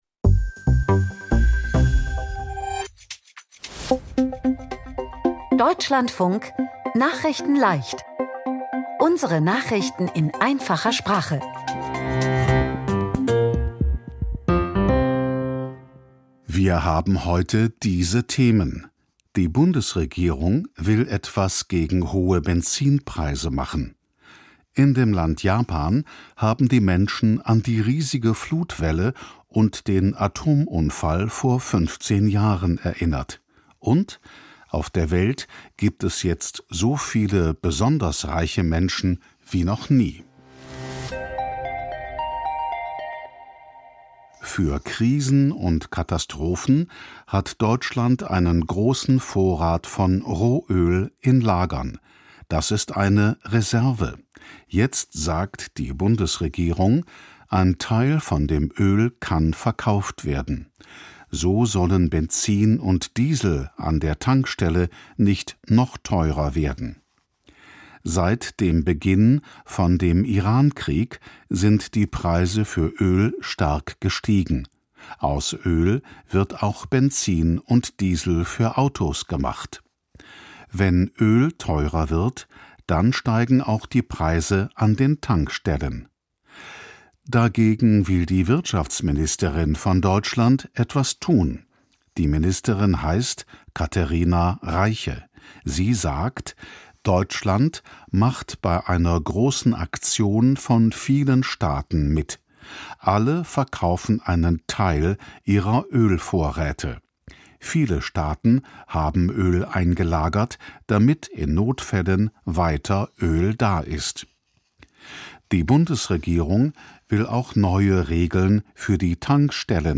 Nachrichten in Einfacher Sprache vom 11.03.2026